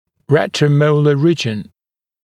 [ˌretrə(u)’məulə ‘riːʤən][ˌрэтро(у)’моулэ ‘ри:джэн]ретромолярная область